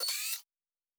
pgs/Assets/Audio/Sci-Fi Sounds/Weapons/Additional Weapon Sounds 3_4.wav at master
Additional Weapon Sounds 3_4.wav